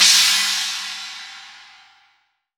Index of /90_sSampleCDs/AKAI S6000 CD-ROM - Volume 3/Crash_Cymbal2/CHINA&SPLASH
8SA CYMB.WAV